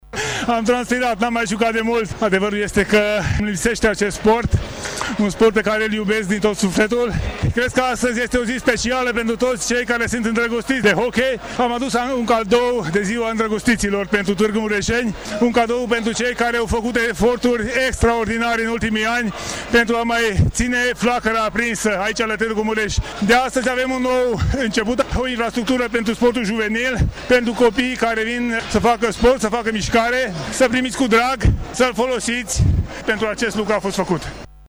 Fostul președinte al Federației Române de Hochei, Tanczos Barna: